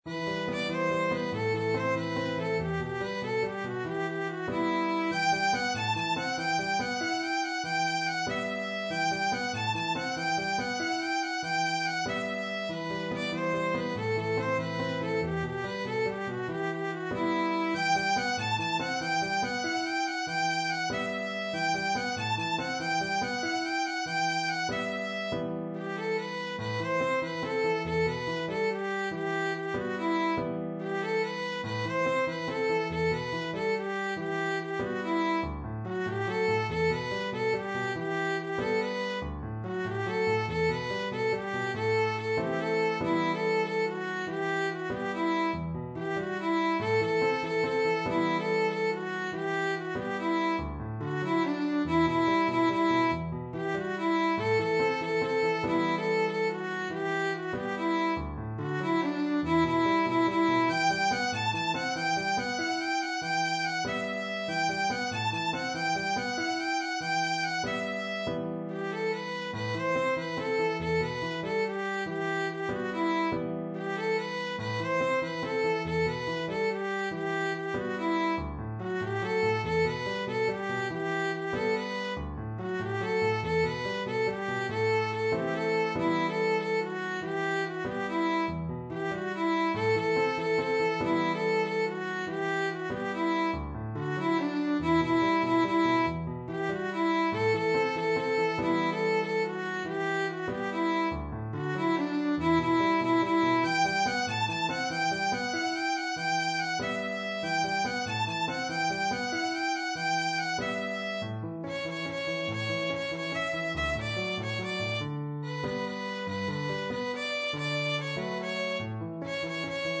سطح : متوسط
ویولون